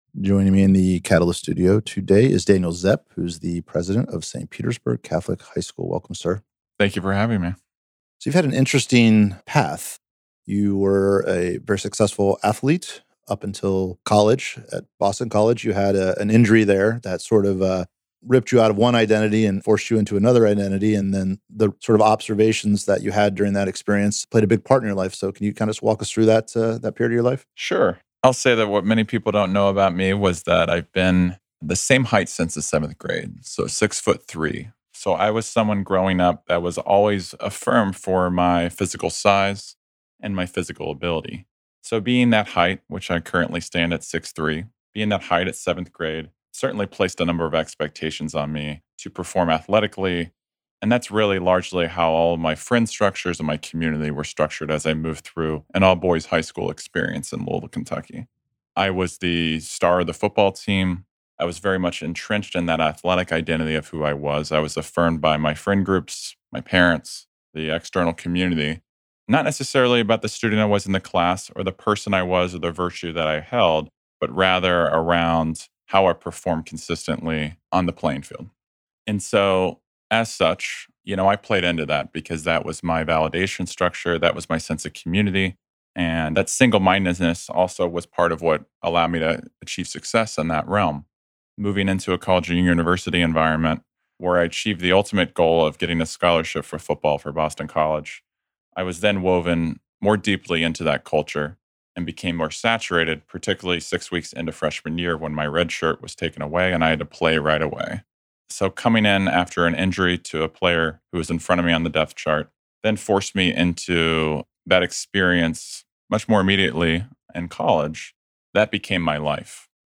In this long-form conversation